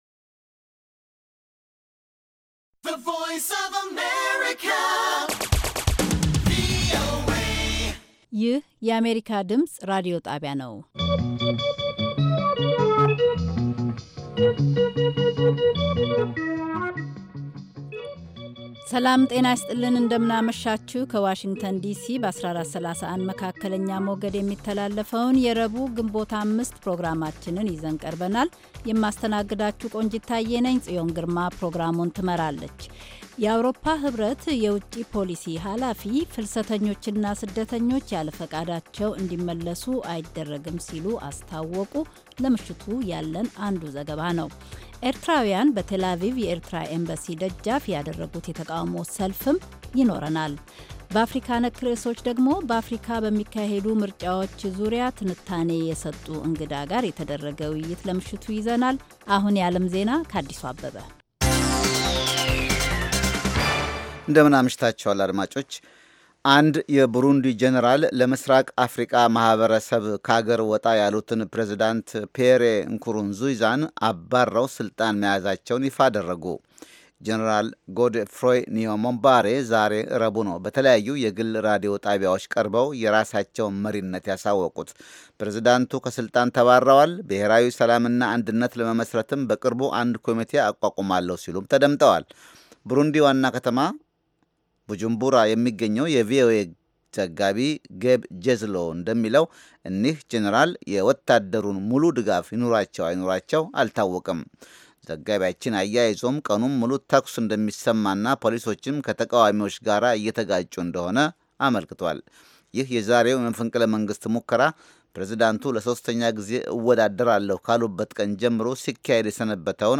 ከምሽቱ ኣንድ ሰዓት የአማርኛ ዜና